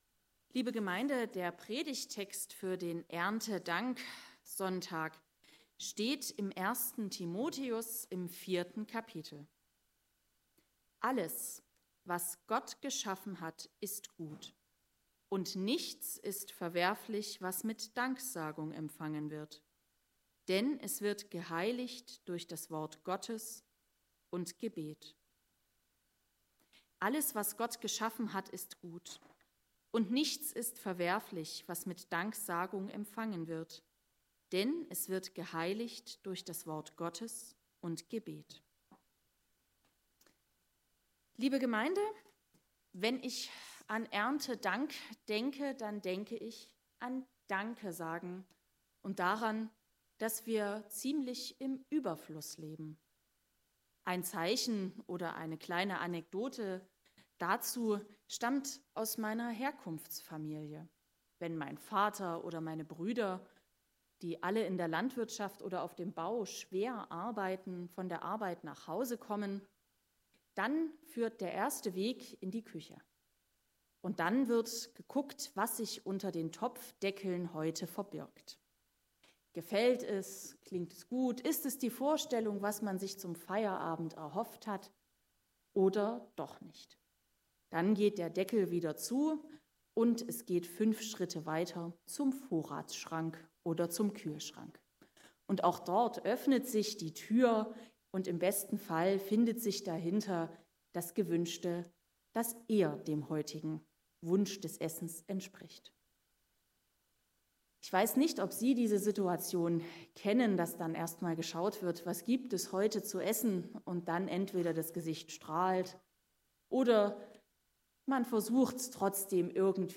Gottesdienstart: Erntedankgottesdienst